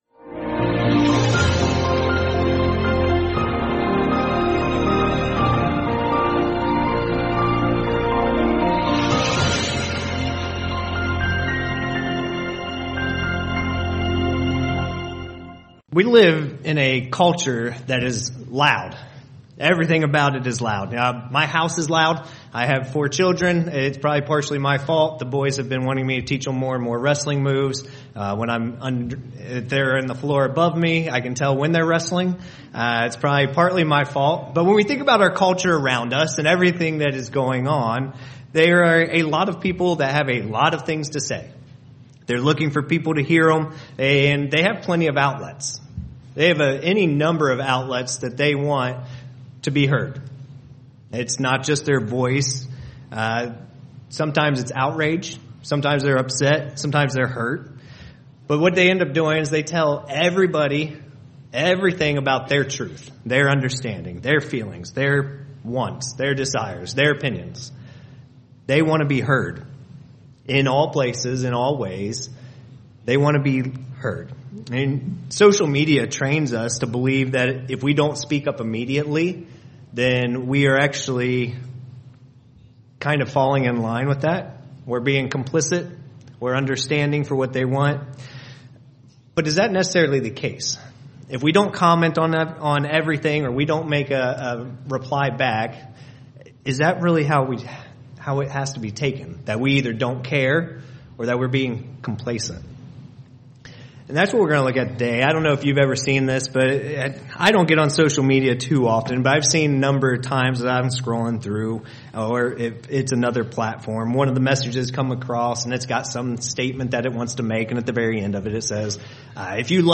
Today's sermon will help us understand when and how to respond in a noisy world.